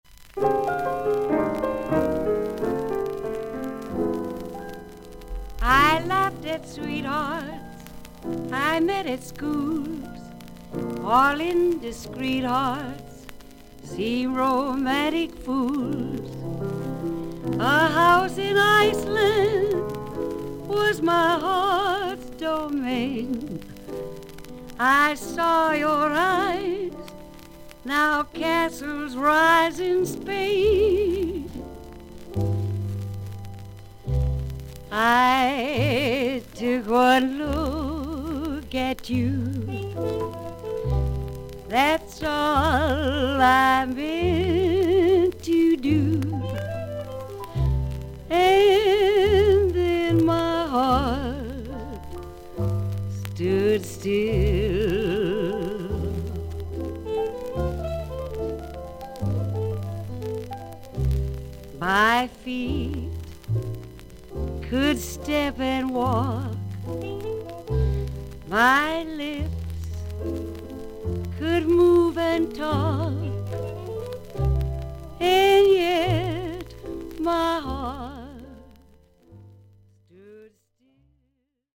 所々に少々軽いパチノイズの箇所あり。少々サーフィス・ノイズあり。音はクリアです。
30年代から活躍する麗しい女性シンガー。